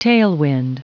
Prononciation du mot tailwind en anglais (fichier audio)
Prononciation du mot : tailwind